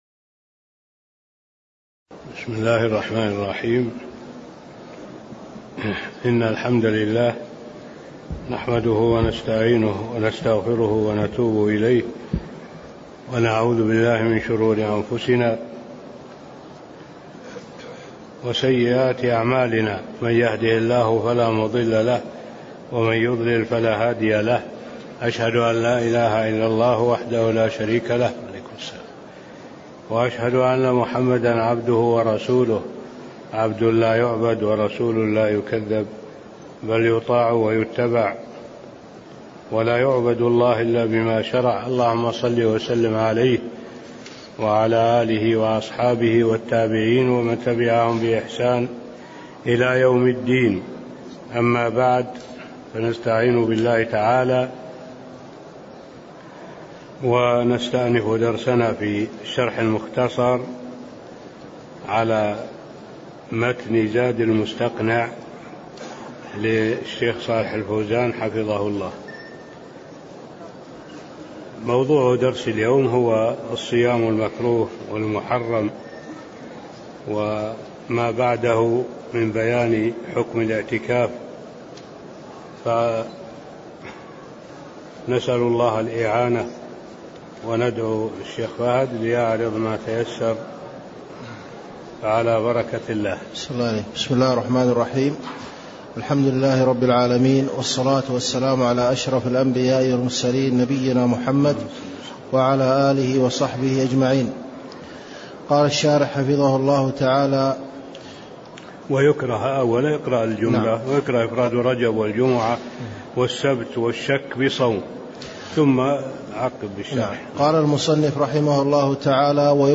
تاريخ النشر ١٧ شوال ١٤٣٤ هـ المكان: المسجد النبوي الشيخ: معالي الشيخ الدكتور صالح بن عبد الله العبود معالي الشيخ الدكتور صالح بن عبد الله العبود باب الصوم المكروه والمحرم (10)